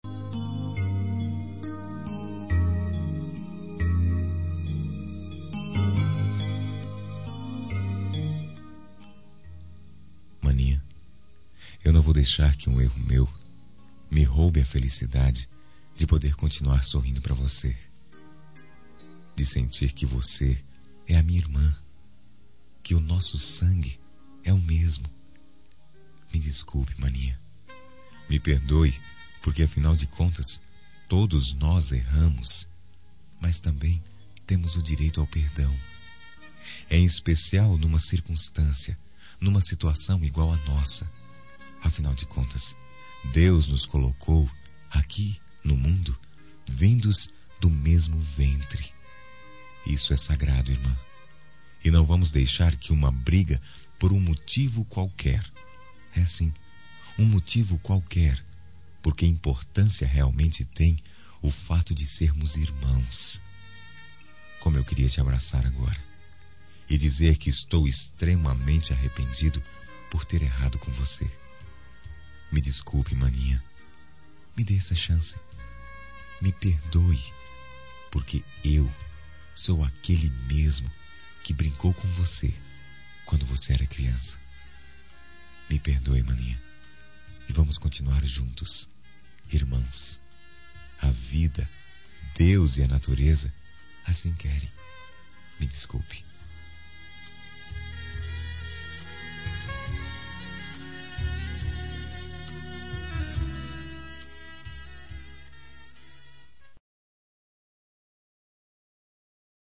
Reconciliação Familiar – Voz Masculina – Cód: 088740 – Irmã